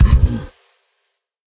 Minecraft Version Minecraft Version snapshot Latest Release | Latest Snapshot snapshot / assets / minecraft / sounds / mob / camel / dash5.ogg Compare With Compare With Latest Release | Latest Snapshot